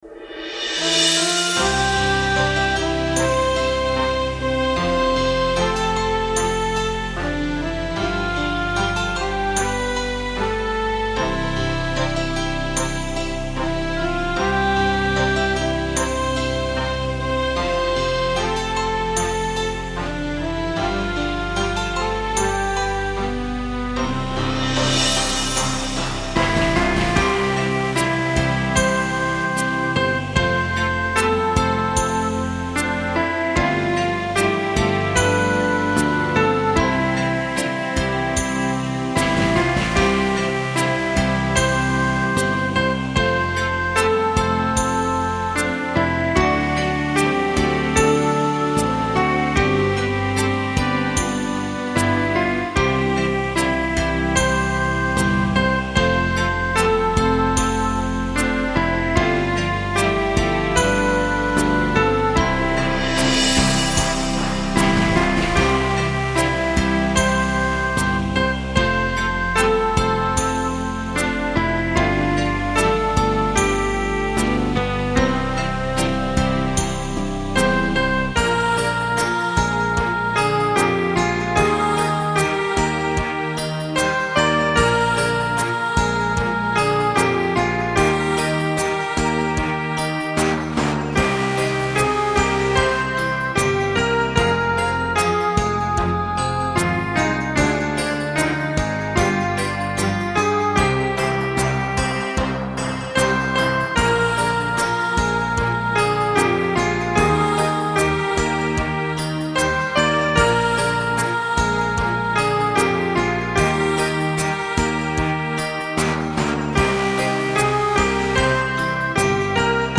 HIMNO AL PIANO
Himno a la residencia. Versi�n piano